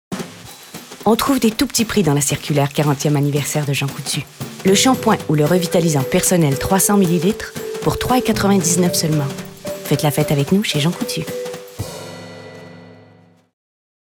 DÉMO VOIX
Comédienne, auteur, metteur en scène